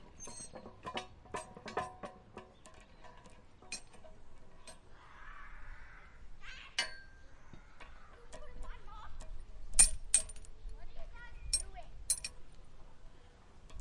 自行车链条和齿轮
描述：自行车链条和齿轮
标签： 车轮 行驶 OWI 自行车 踏板 落后 金属 骑车人 齿轮 车链
声道立体声